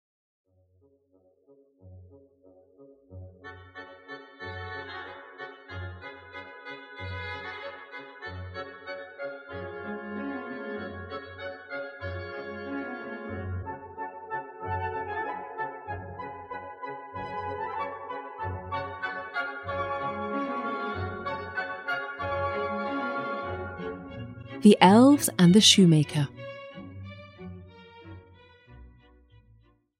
Ukázka z knihy
Audiobook The Elves and the Shoe maker, a Fairy Tale by Brothers Grimm.